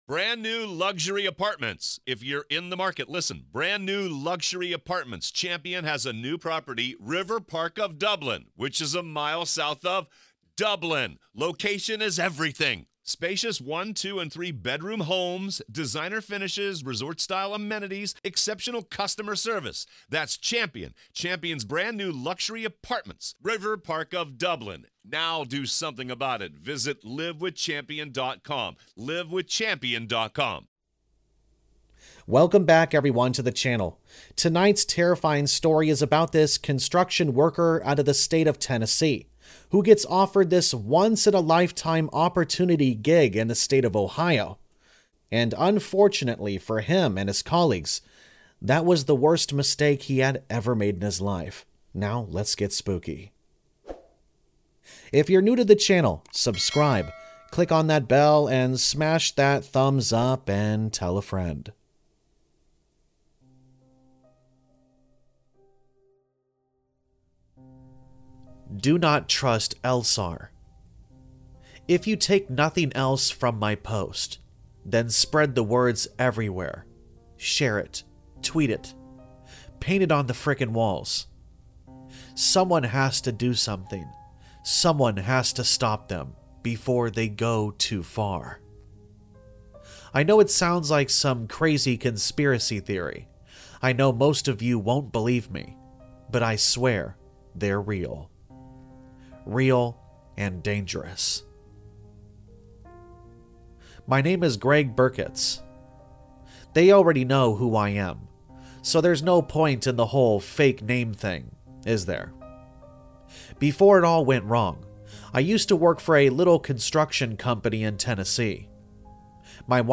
Campfire Tales is a channel which is focused on Allegedly True Scary Stories and Creepypastas.